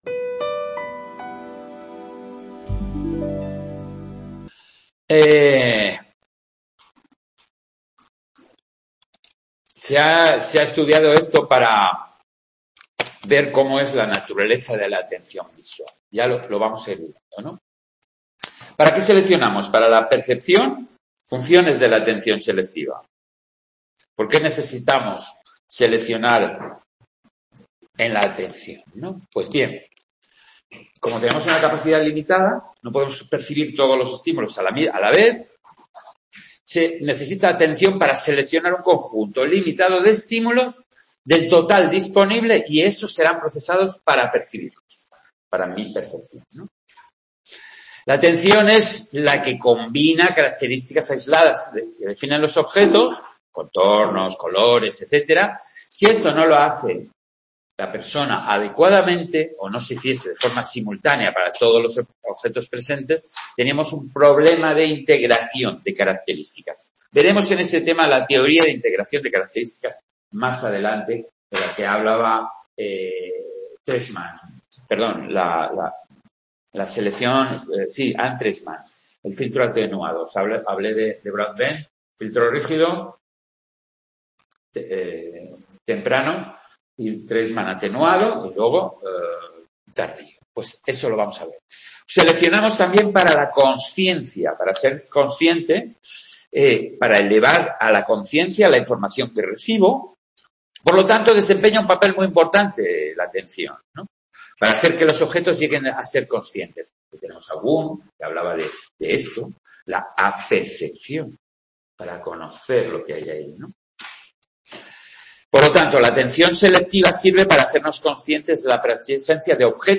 Tema 3 de Psicología de la Atención grabado en Sant Boi